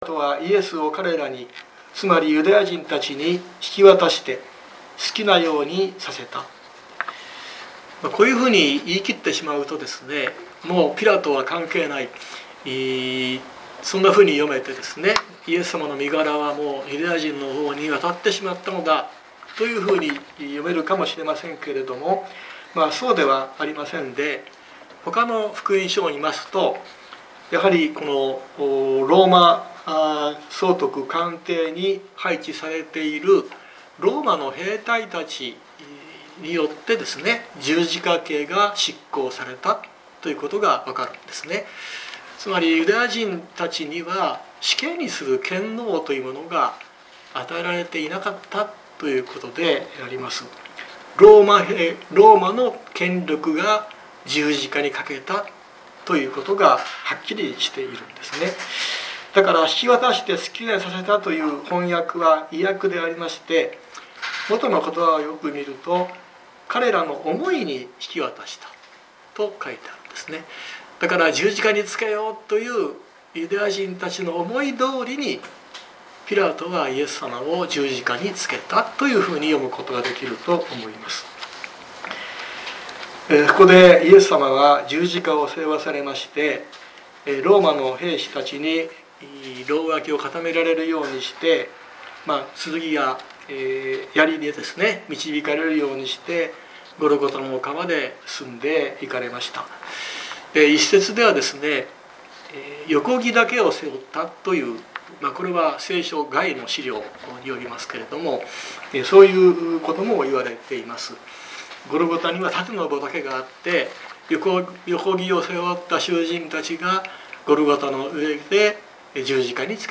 2024年03月17日朝の礼拝「ヴィ・ドロローサ」熊本教会
熊本教会。説教アーカイブ。